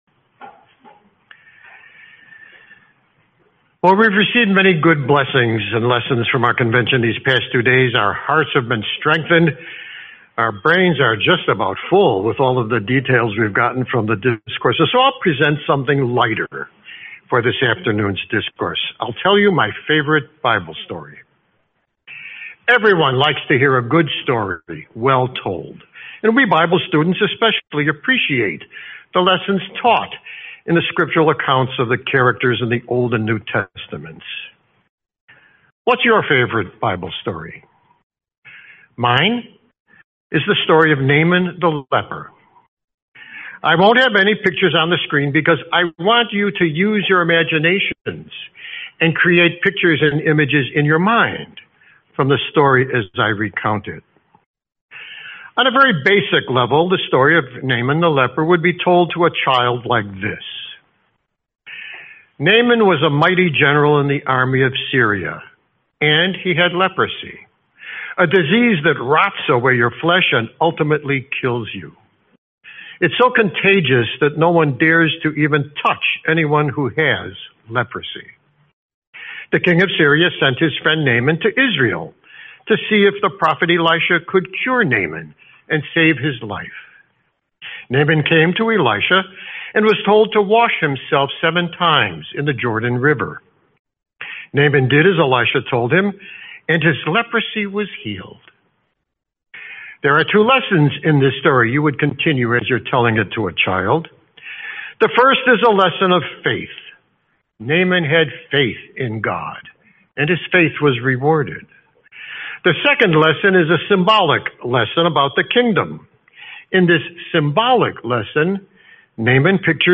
Series: 2026 Phoenix Convention